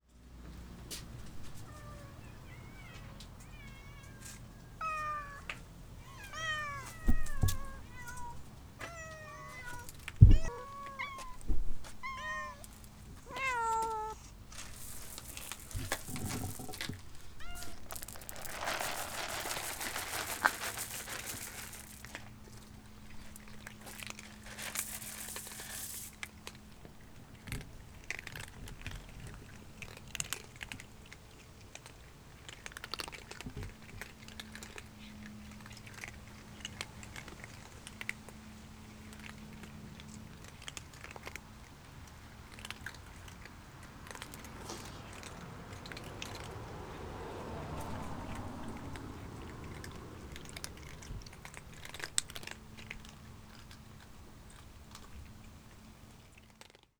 Tetrahedral Ambisonic Microphone
Credits: Eleven hungry feral cats who roam the neighborhood looking for food.